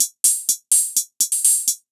Index of /musicradar/ultimate-hihat-samples/125bpm
UHH_ElectroHatB_125-01.wav